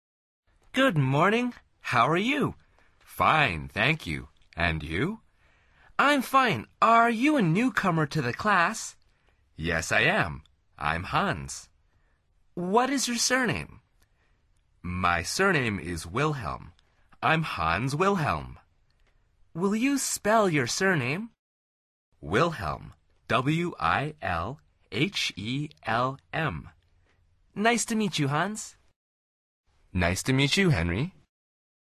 Pulsa las flechas de reproducción para escuchar el primer diálogo de esta lección. Al final repite el diálogo en voz alta tratando de imitar la entonación de los locutores.